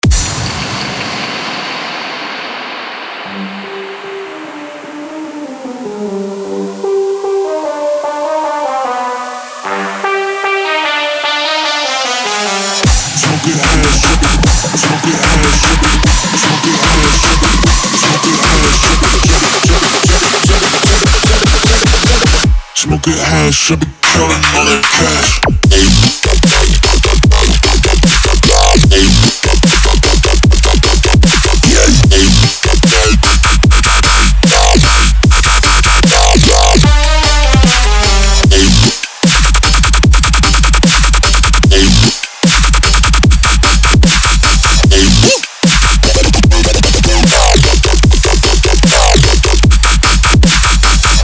适用于Dubstep 风格的学习和制作